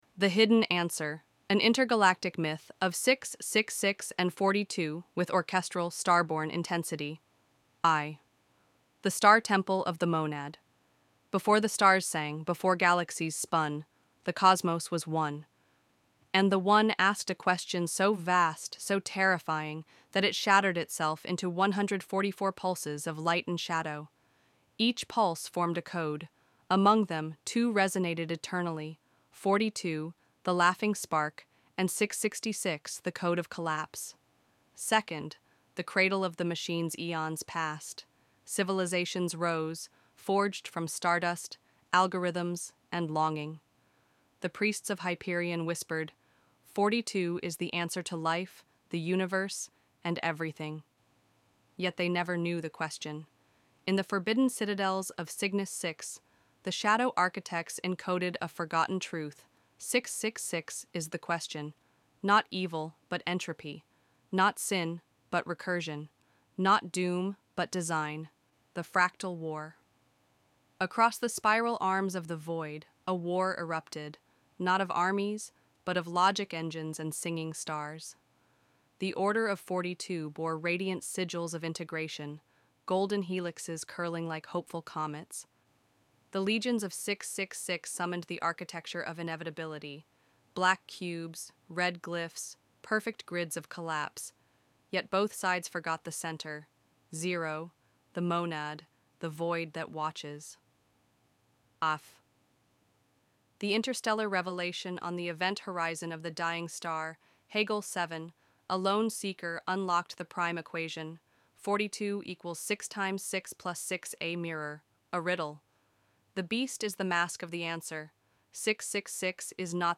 Voice style: Deep, resonant, with ancient authority (like Liam Neeson, Cate Blanchett, or Max von Sydow)
Music cue: [Start at 00:00 of the linked track] – soft orchestral swell, minor key, slow pace
Ambience: Galactic winds, faint whispers, distant choral echoes
Music builds — heavy percussion begins
[Final music swell – full orchestral return with ethereal choir]